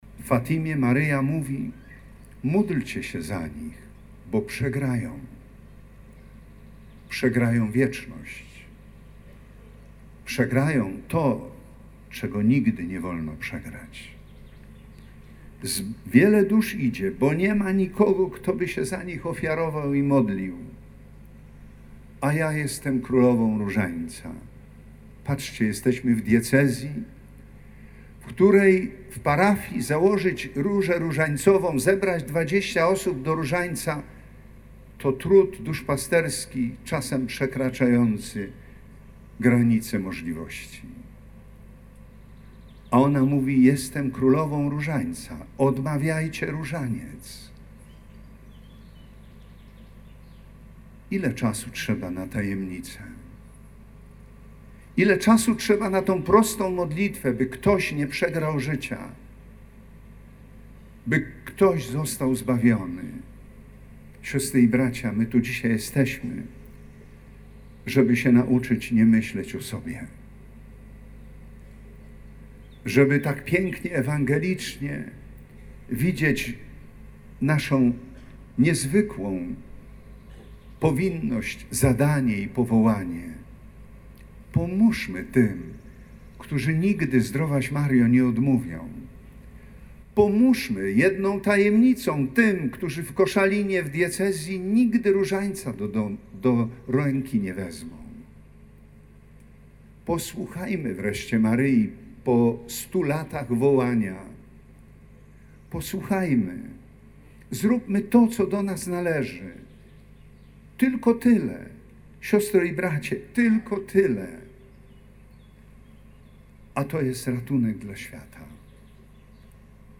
W Koszalinie, w 100. rocznicę objawień w Fatimie wierni uczestniczyli w Wielkiej Procesji Fatimskiej. Poprzedziła ją Msza św. na Górze Chełmskiej.